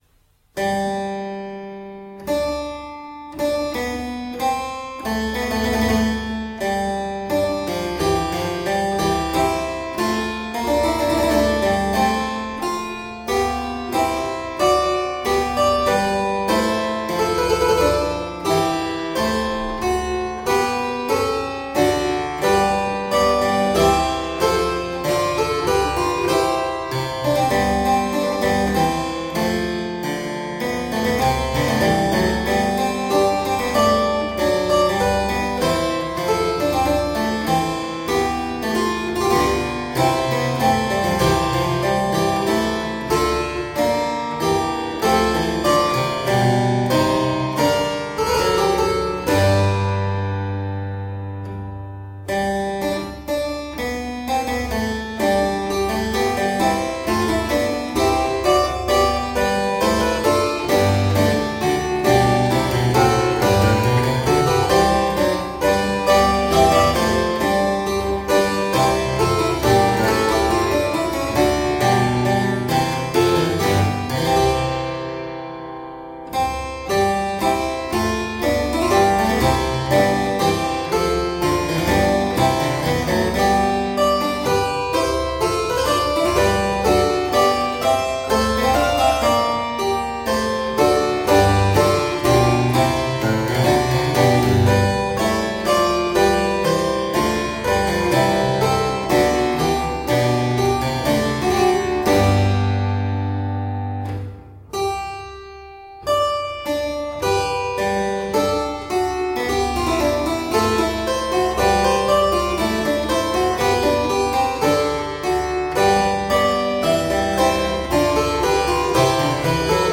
Solo harpsichord music
Classical, Baroque, Instrumental Classical
Harpsichord